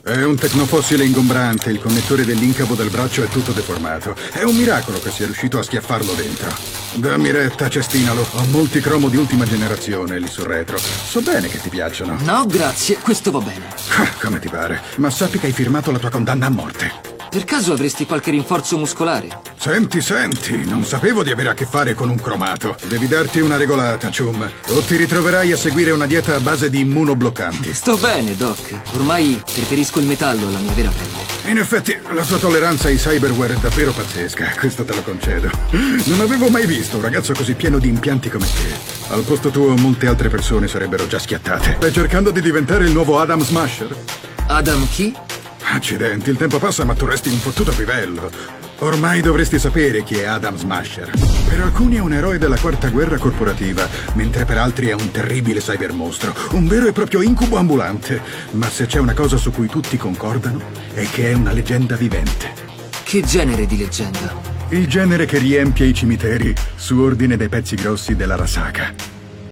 nel cartone animato "Cyberpunk: Edgerunners", in cui doppia Doc.